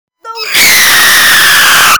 Reactions
Screaming Funny